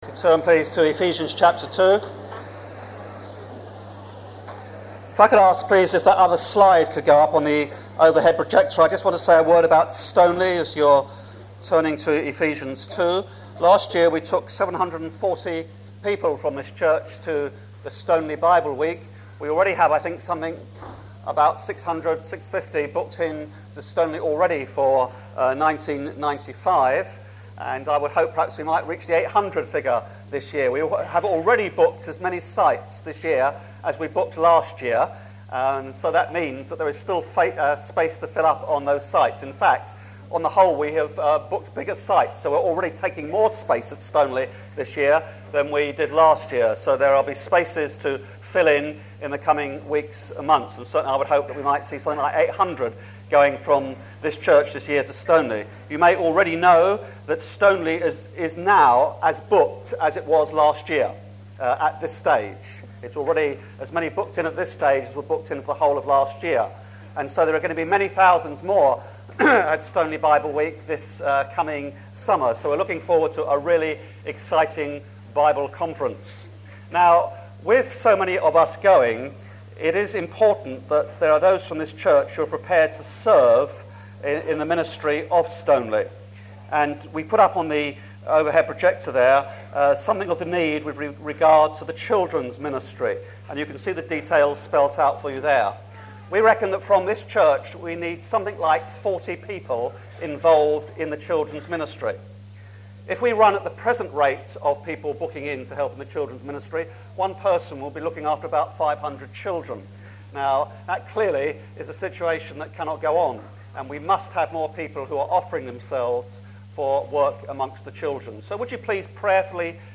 Clarendon Church, Brighton, Archive Sermons